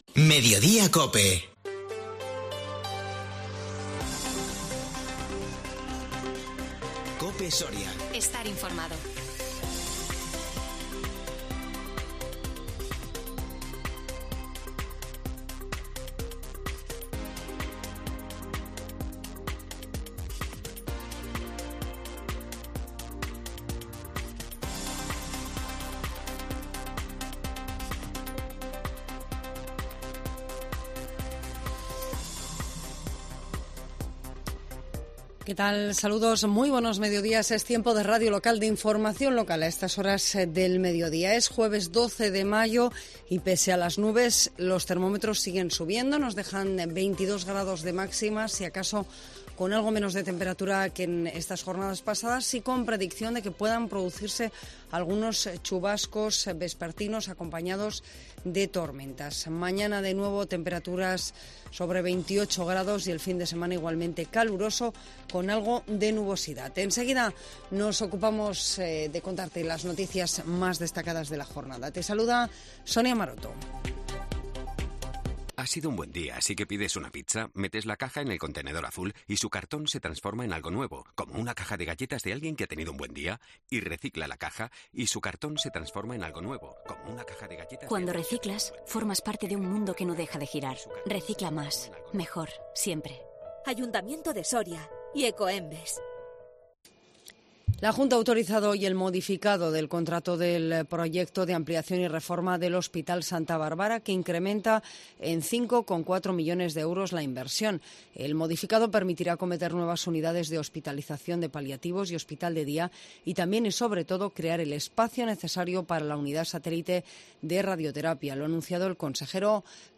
INFORMATIVO MEDIODÍA COPE SORIA 12 MAYO 2022